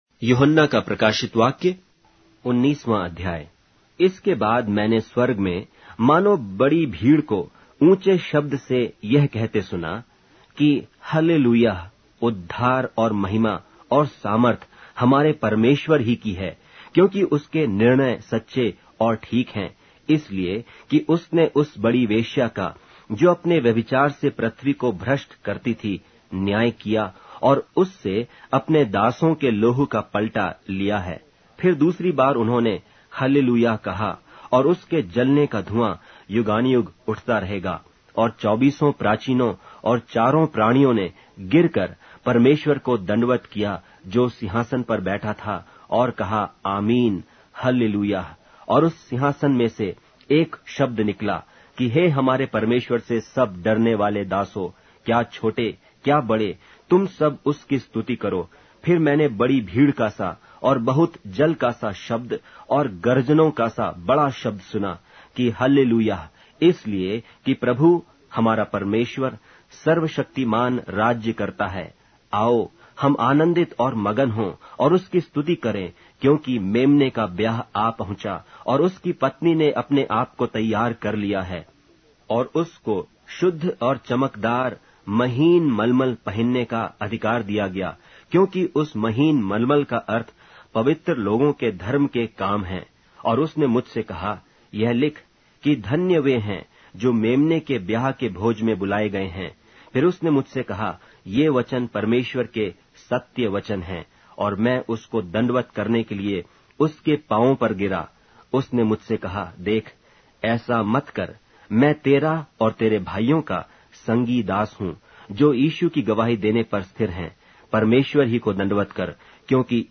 Hindi Audio Bible - Revelation 11 in Erven bible version